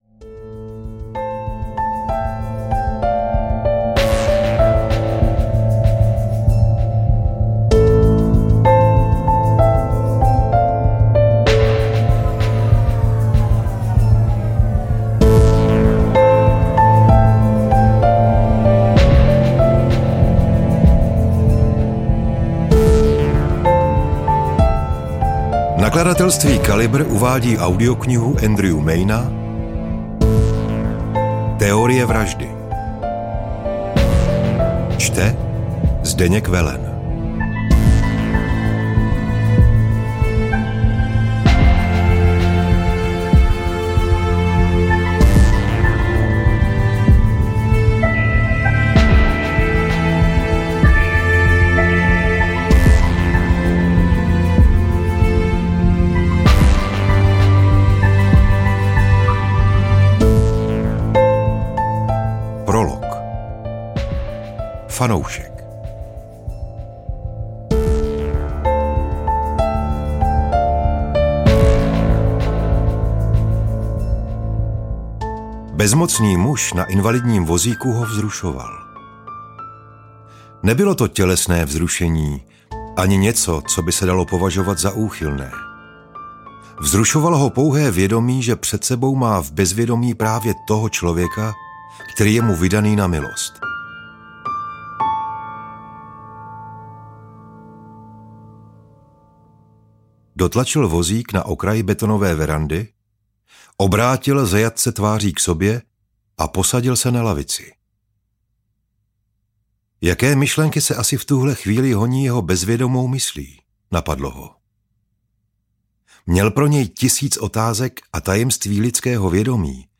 Teorie vraždy audiokniha
Ukázka z knihy
teorie-vrazdy-audiokniha